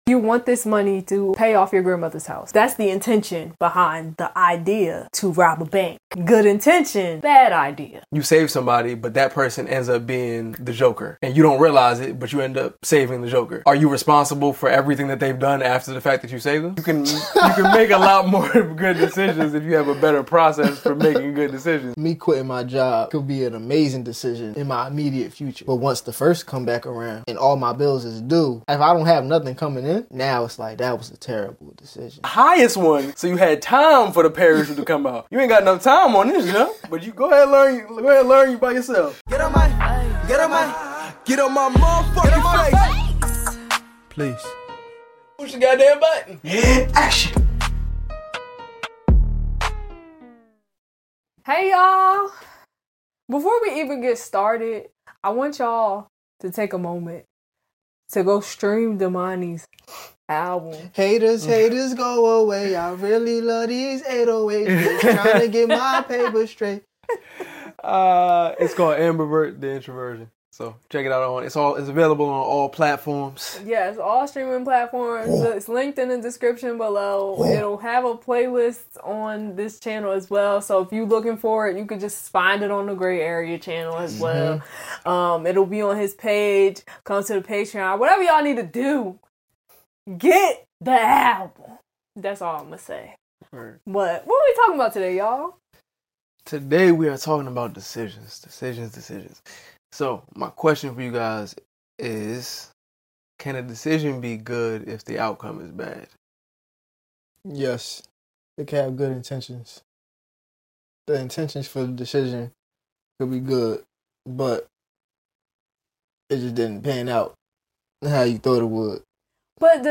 Through uncommon topics that make us laugh when we shouldn’t, we make conversations fun again.